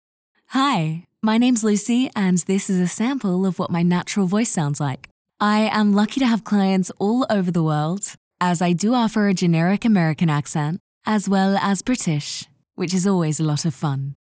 Female
English (Australian)
Natural Speak
Natural Speaking Tone
Words that describe my voice are Authentic, Professional, Conversational.
All our voice actors have professional broadcast quality recording studios.